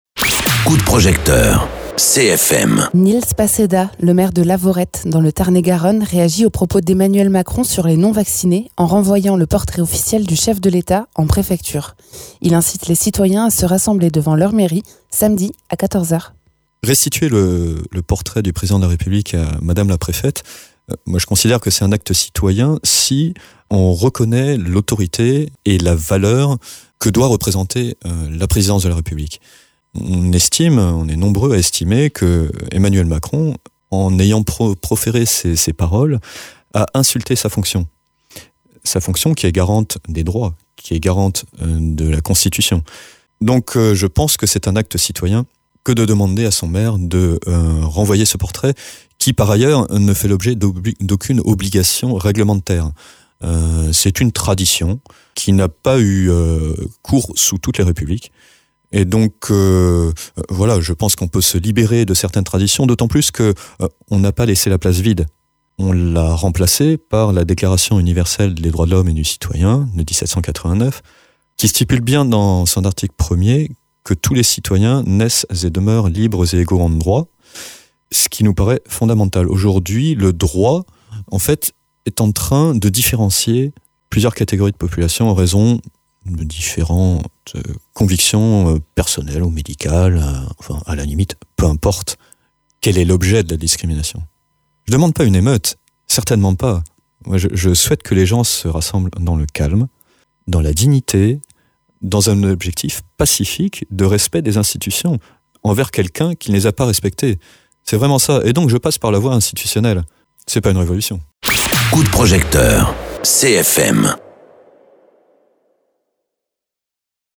Interviews
Invité(s) : Nils Passedat, maire de Lavaurette